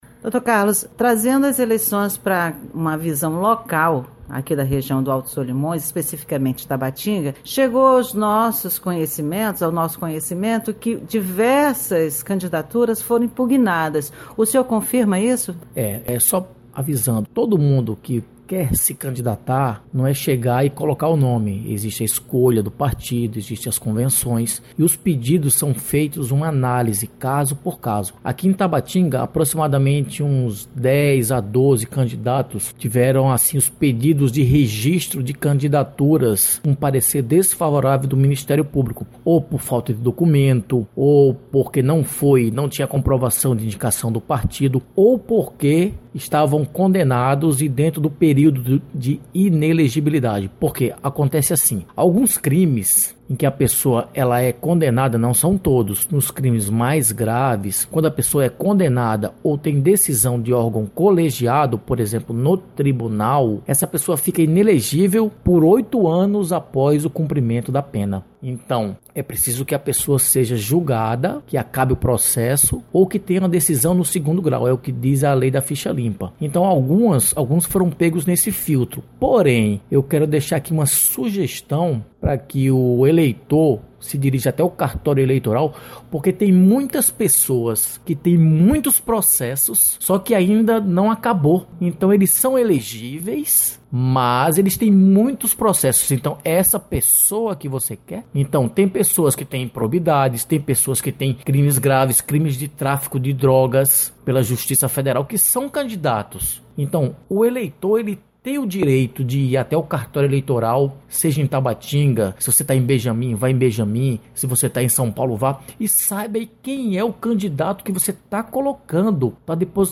Sobre o assunto, o entrevistado foi o promotor de Justiça do município, Carlos Firmino Dantas.